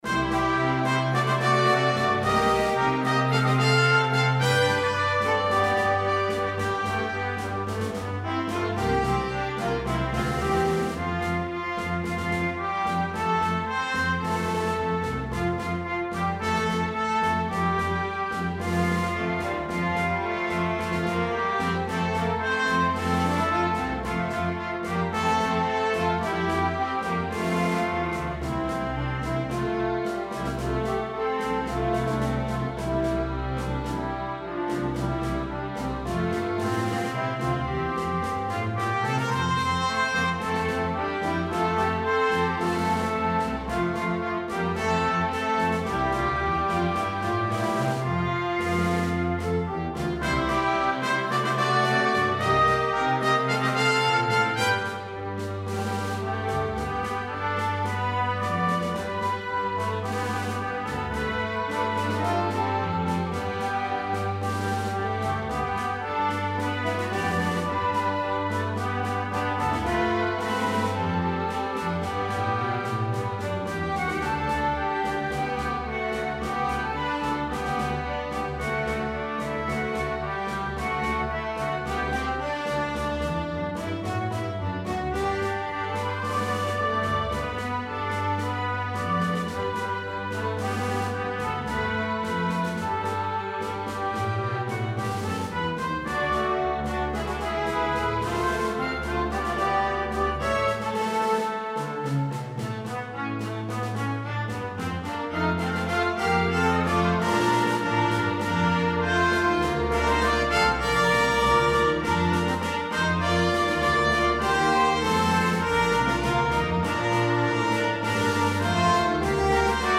A joyful and enthusiastic arrangement of this hymn.
Arranged in 4 parts, fully orchestrated.